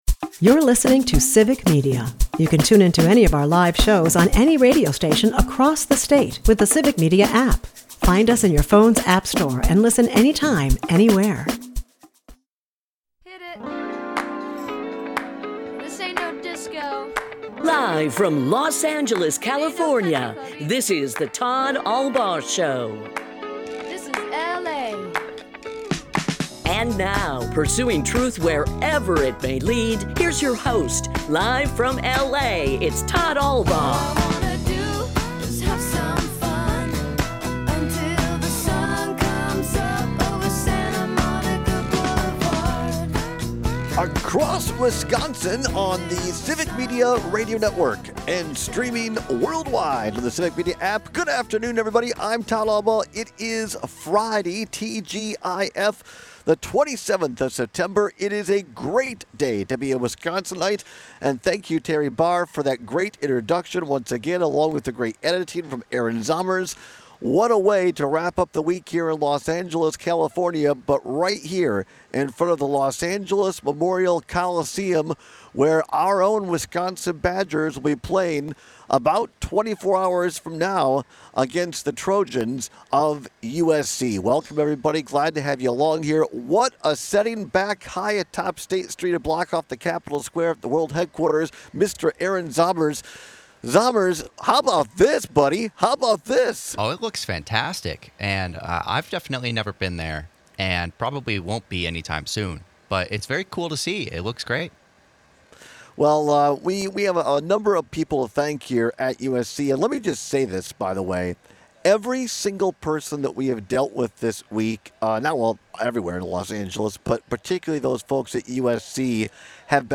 LIVE From the Los Angeles Memorial Coliseum!